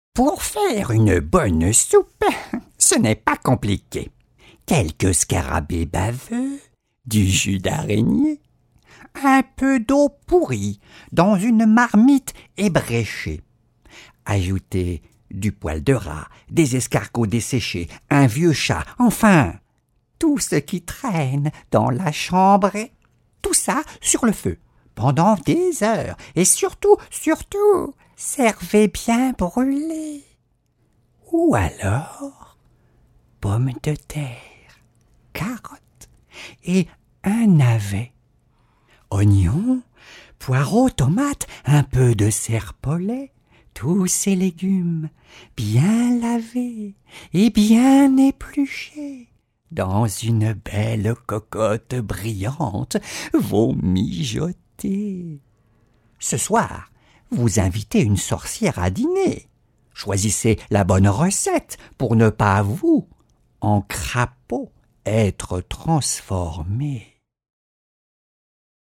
La comptine enregistrée
comptine-sorciere-soupe-a-la-sorciere.mp3